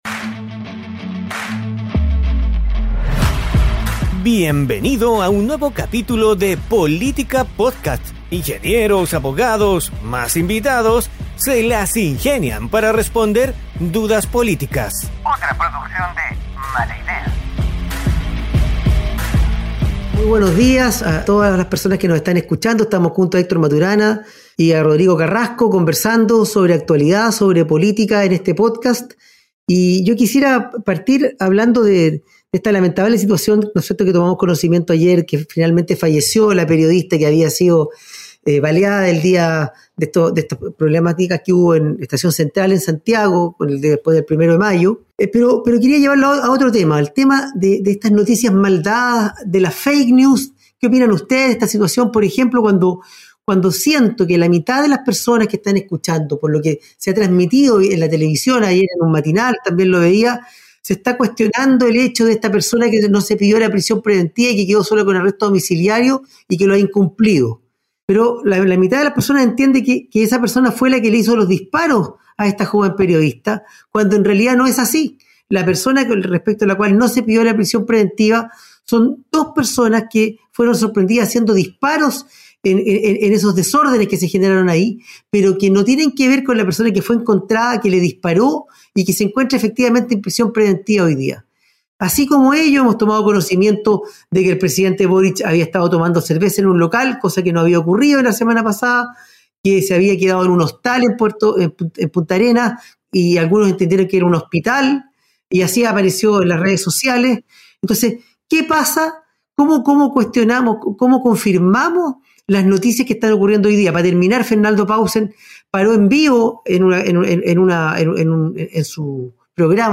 En este Política Podcast dos ingenieros
un abogado